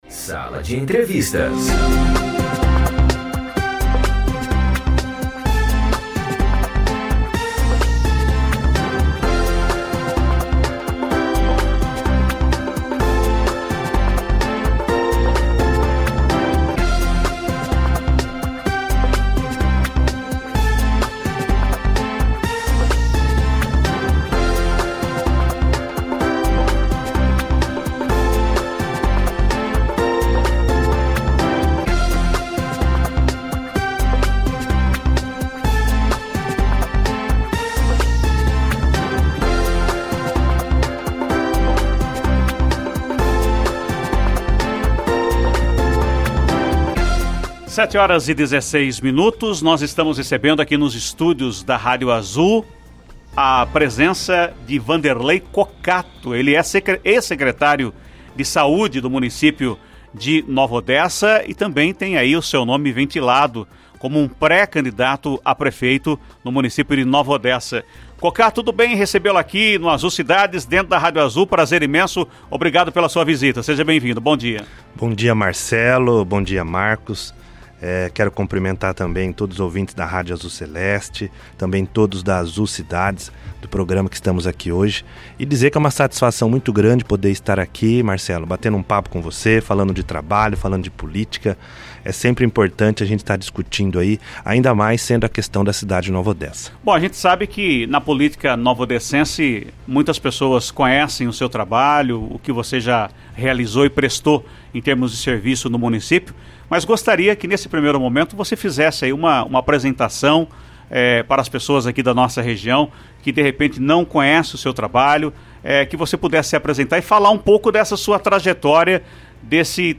Entrevista com Vanderlei Cocato, ex-secretário de Saúde de Nova Odessa
Vanderlei Cocato, Ex-secretário de Saúde de Nova Odessa, concedeu entrevista exclusiva ao Sala de Entrevistas, do Azul Cidades na manhã desta segunda-feira, 05 de fevereiro de 2024.